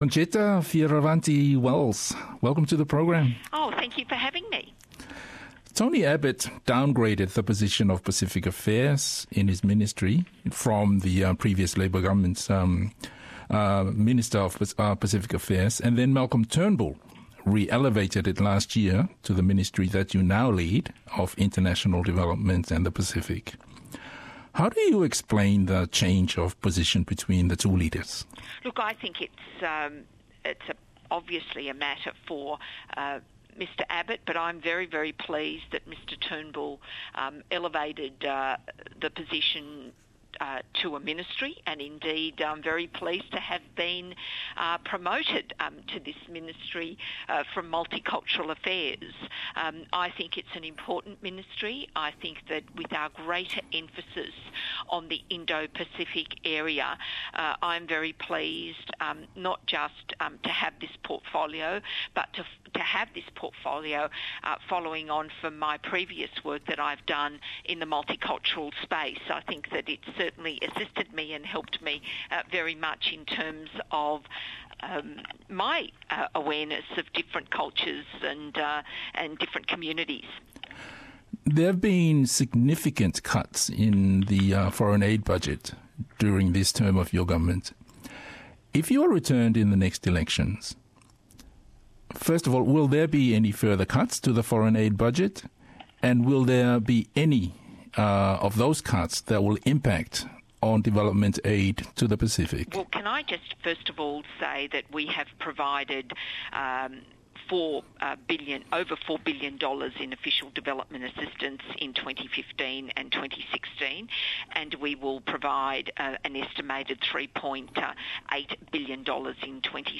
I se talanoaga